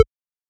edm-perc-01.wav